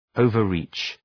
Shkrimi fonetik {,əʋvər’ri:tʃ}
overreach.mp3